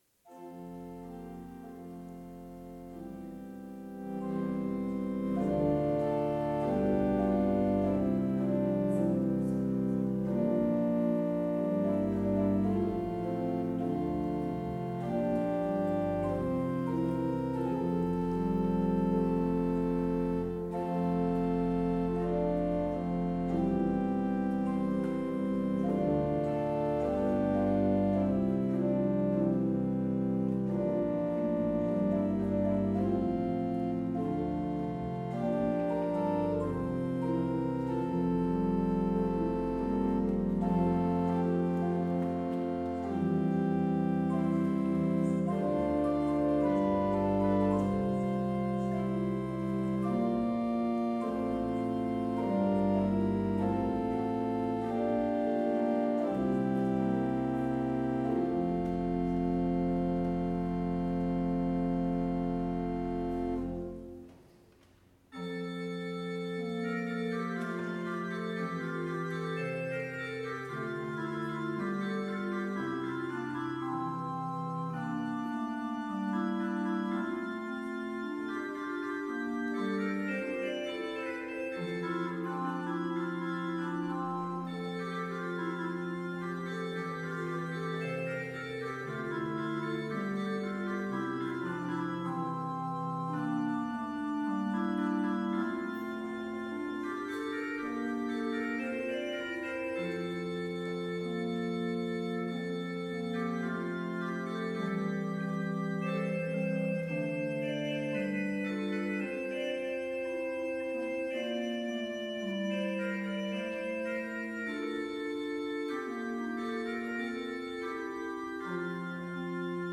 Complete service audio for Chapel - Wednesday, June 26, 2024